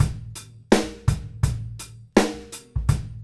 GROOVE 150ER.wav